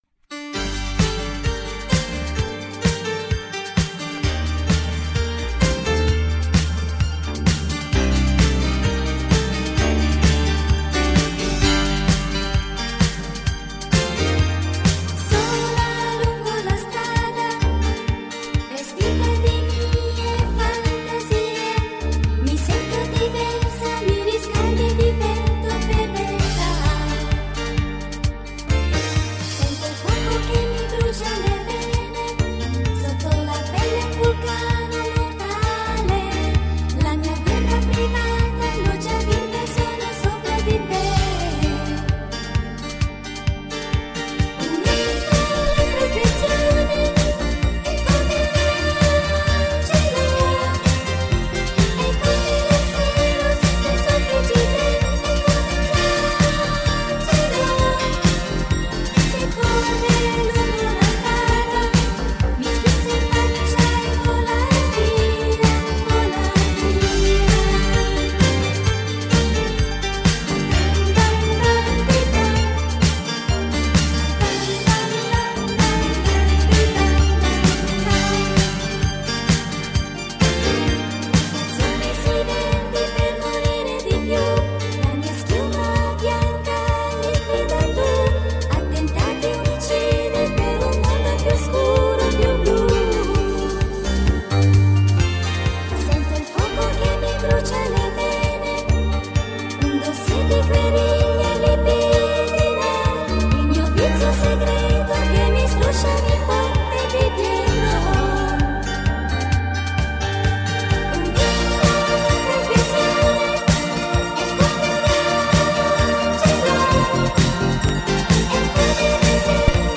موسیقی ایتالیایی
سبک دیسکو